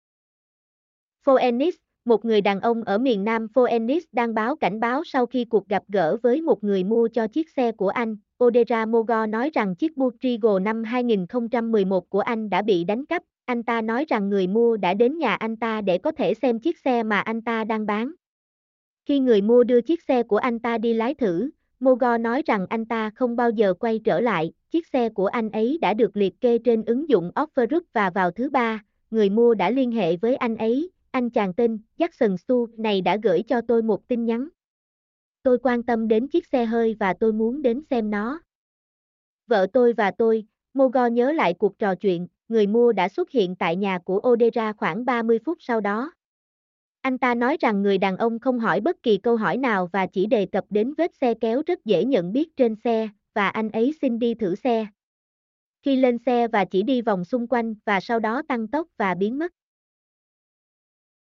mp3-output-ttsfreedotcom-7.mp3